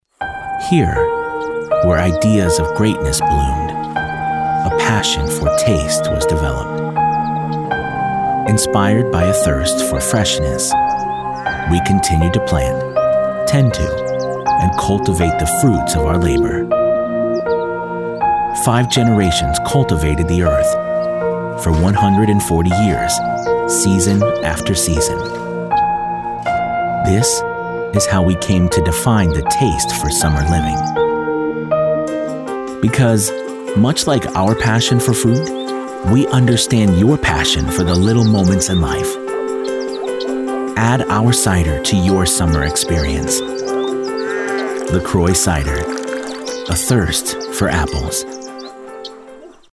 Male
Yng Adult (18-29), Adult (30-50)
My voice-over type can be described as deep, friendly, genuine, authentic, the guy next door, believable, raspy, soft sell, hard sell, sincere, cool, business-like, corporate, relatable, sophisticated, knowledgeable, promo, urgent, and educational.
Television Spots
Poetic Inspiring Introspective
0926Poetic_Inspiring_Introspective_Moving_Sample.mp3